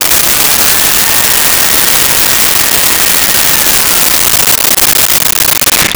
Blender On Blend
Blender on Blend.wav